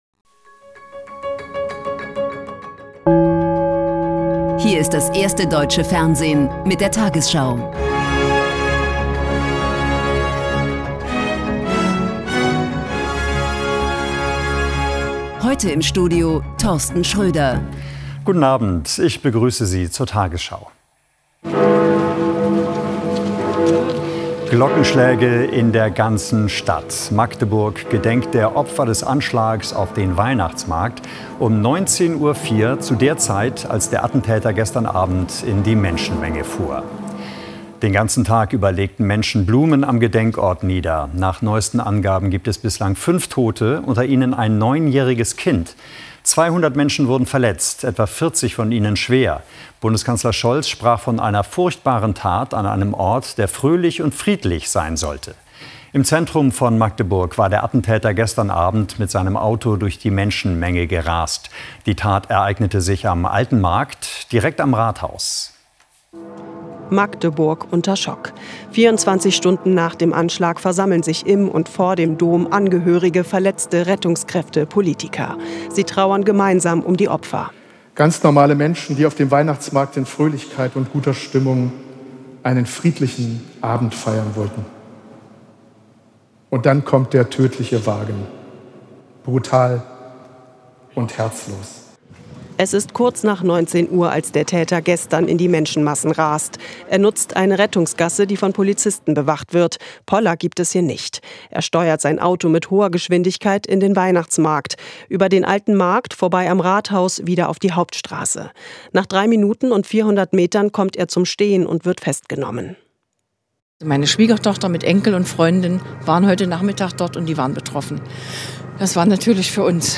Die 20 Uhr Nachrichten von heute zum Nachhören. Hier findet ihr immer die aktuellsten und wichtigsten News.